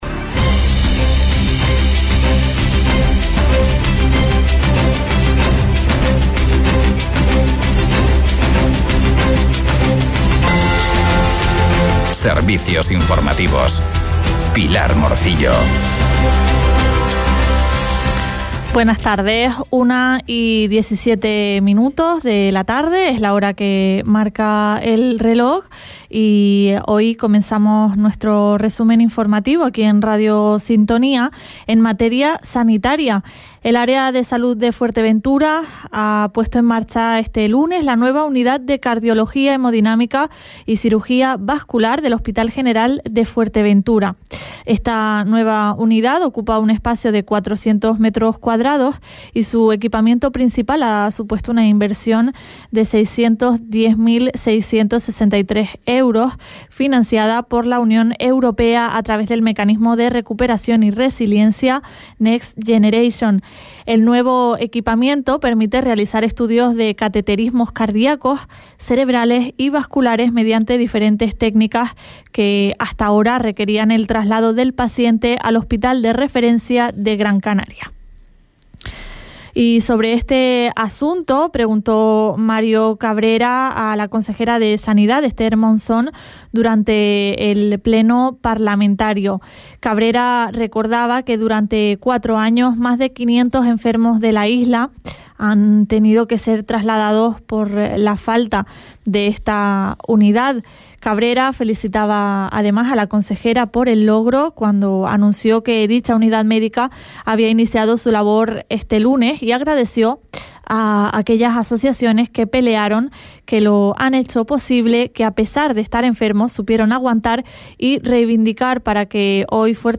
En Radio Sintonía Fuerteventura les mantenemos al día de la actualidad local y regional en nuestros informativos diarios a las 9.30 y 13.15 horas. Por espacio de 15 minutos acercamos a la audiencia lo más destacado de los distintos municipios de la isla, sin perder la atención en las noticias regionales de interés general.